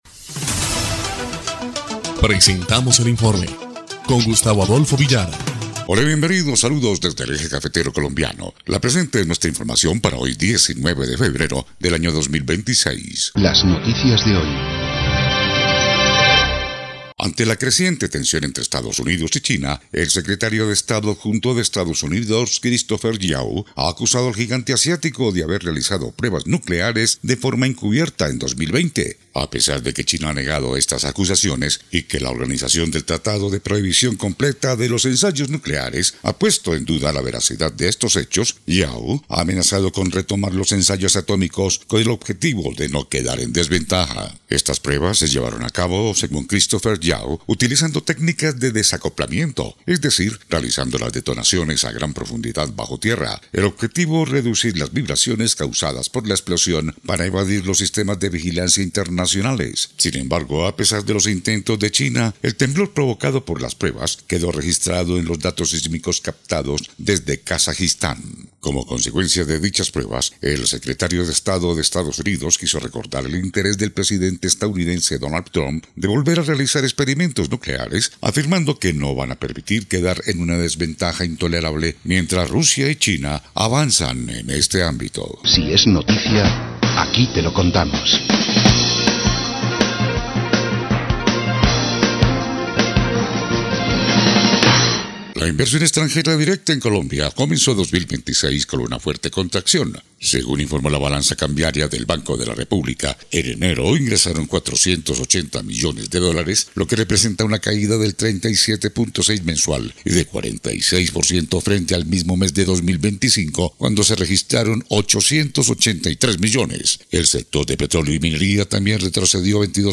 EL INFORME 3° Clip de Noticias del 19 de febrero de 2026